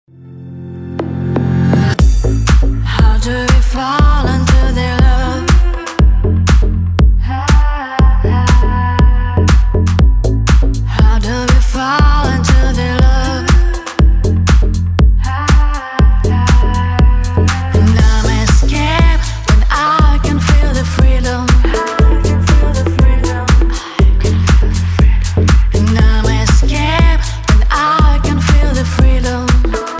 • Качество: 320, Stereo
женский вокал
deep house
Стиль: deep house